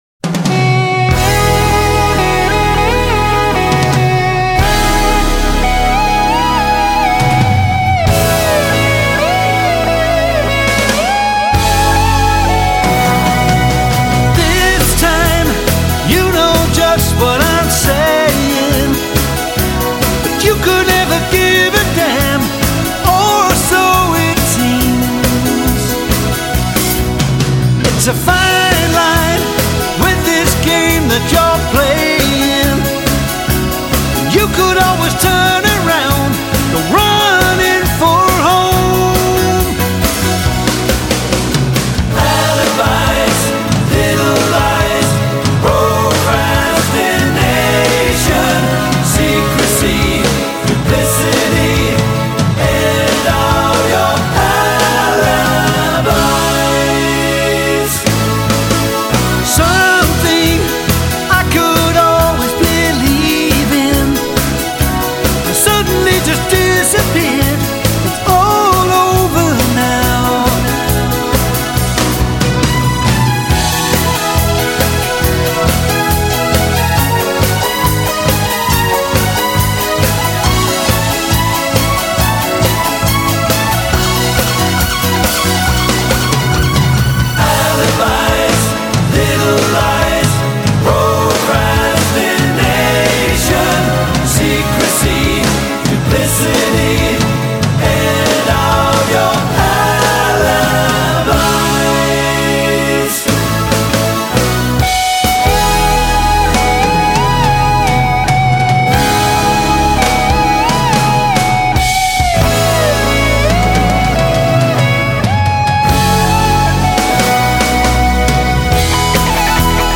as it perfectly recaptured the